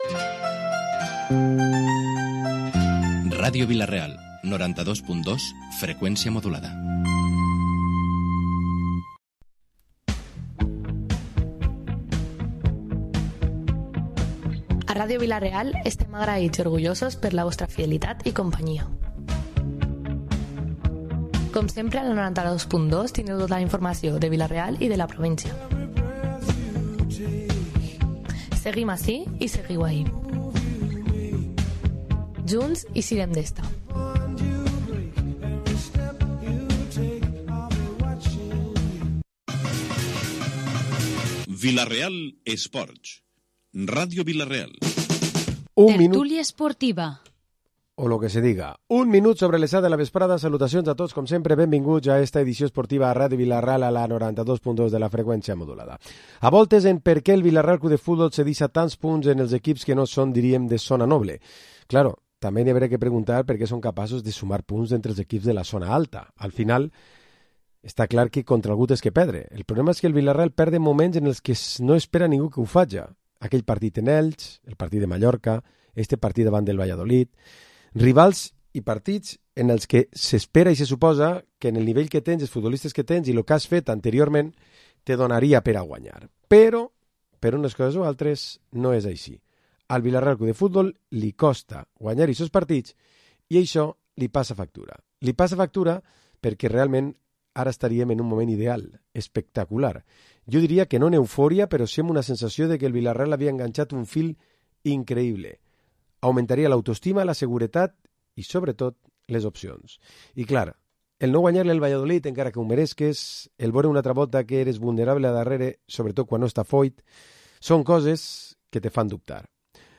Programa esports dilluns tertúlia 17 d’abril